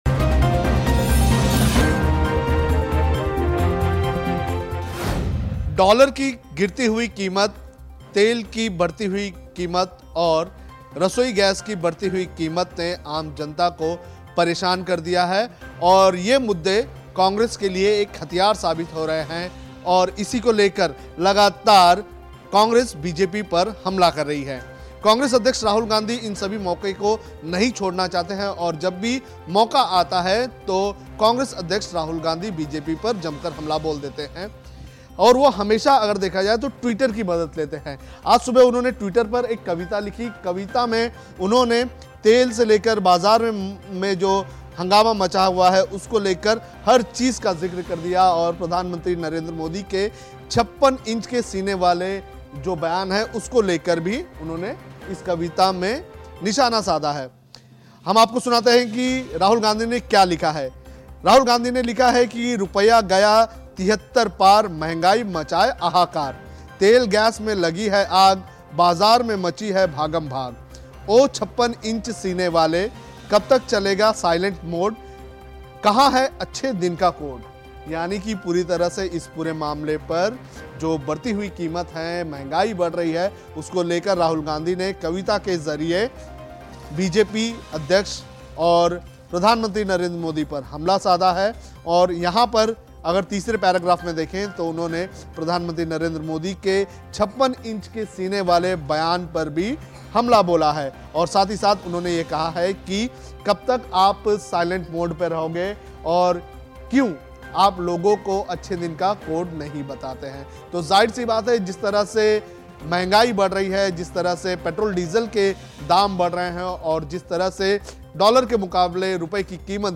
न्यूज़ रिपोर्ट - News Report Hindi / शिव-भक्त के बाद राहुल गाँधी बने कवि, मोदी पर साधा निशाना कहा साइलेंट मोड से उठो